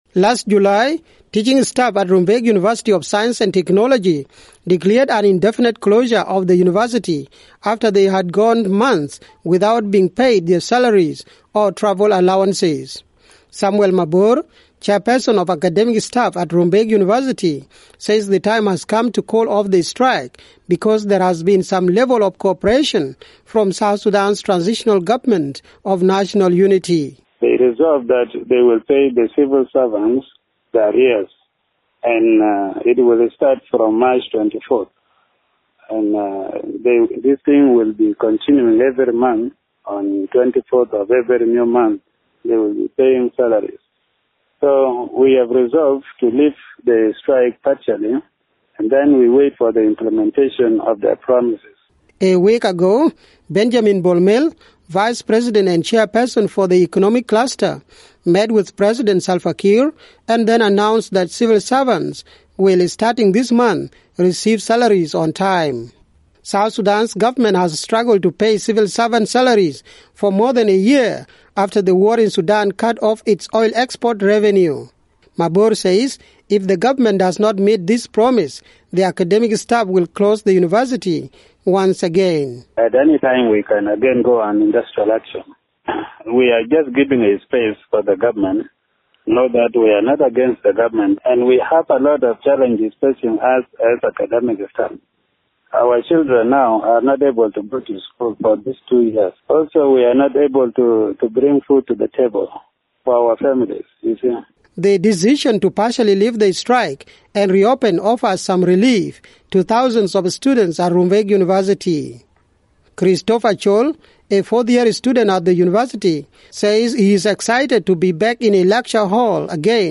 The academic staff at South Sudan’s Rumbek University say they are partially lifting the strike that was declared last year after going months without their salaries. The administration of the teaching staff says the decision was reached after South Sudan’s Vice President Benjamin Bol promised timely payment of salaries and to clear all the outstanding salary arrears. For VOA news